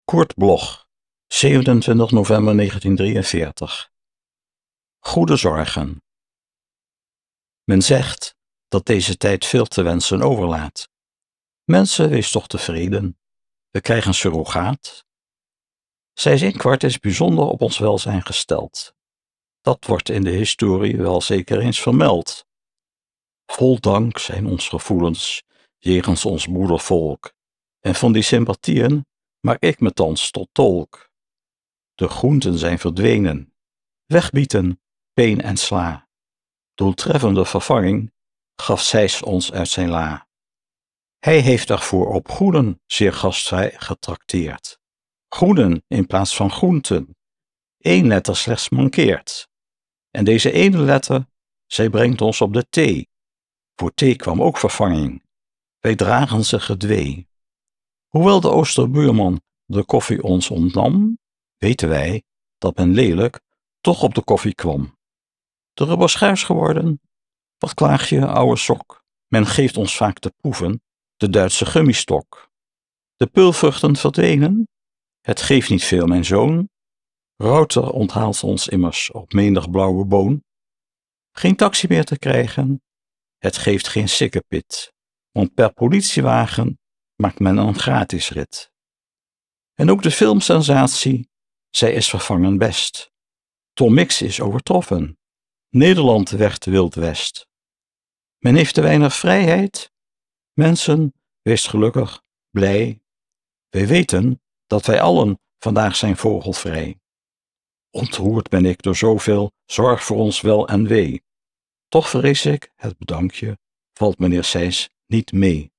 Aufnahme: WORM, Rotterdam · Bearbeitung: Kristen & Schmidt, Wiesbaden